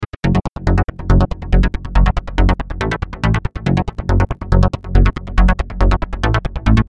厚重的低音
描述：minator合成器，厚重的预设，切断了。
Tag: 140 bpm Electro Loops Bass Loops 1.15 MB wav Key : Unknown